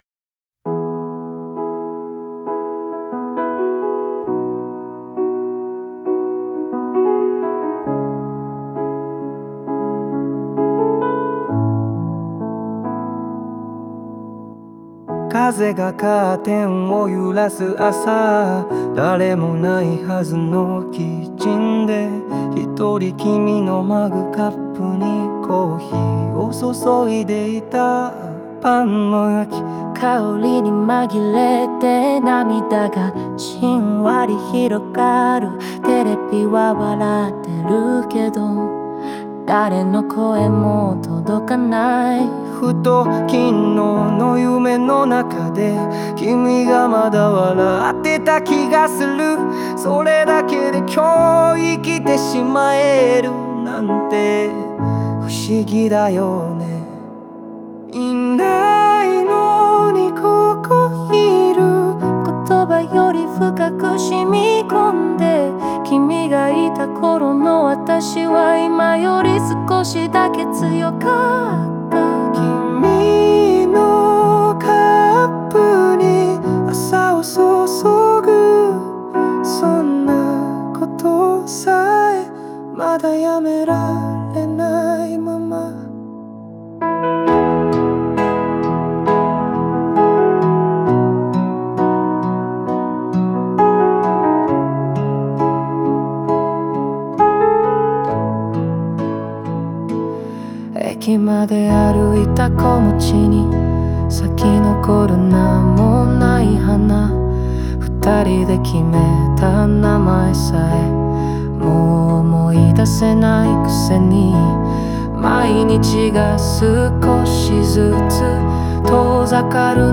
日常の描写を通して、誰かを思い続けることの強さと優しさが胸に染みるバラードです。